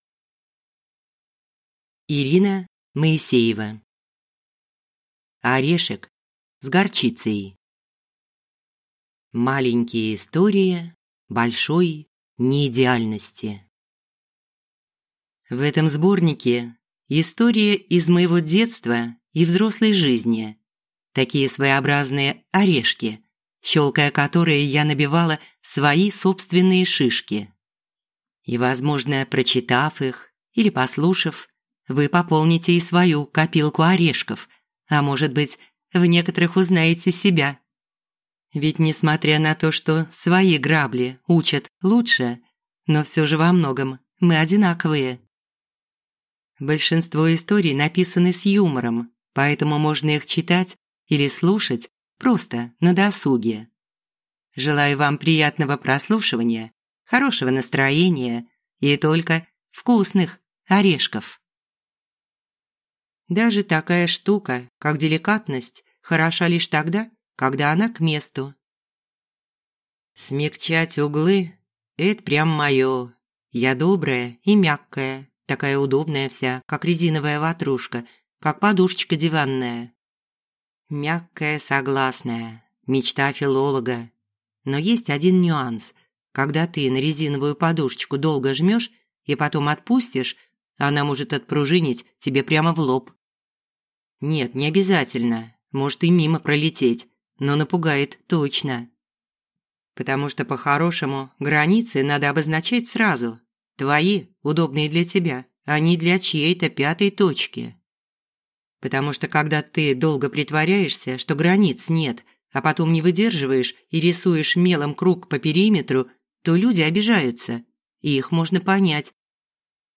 Аудиокнига Орешек с горчицей | Библиотека аудиокниг